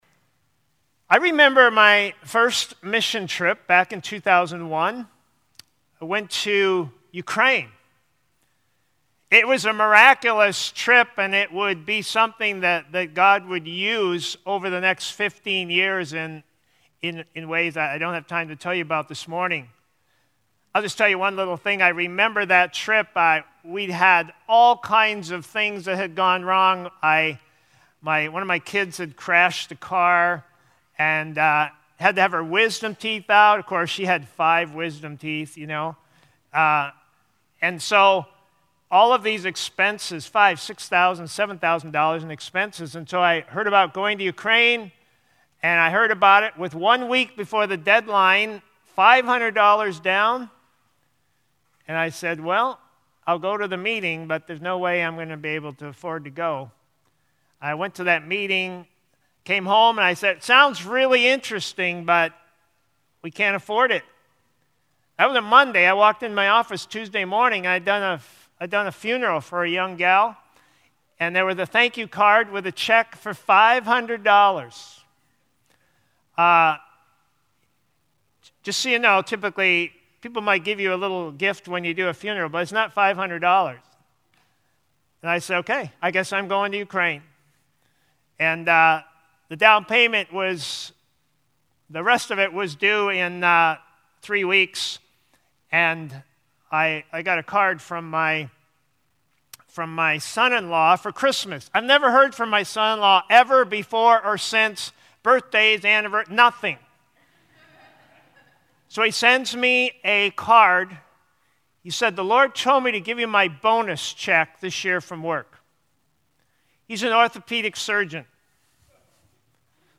First-Baptist-Sermon-July-4-2021.mp3